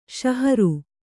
♪ śaharu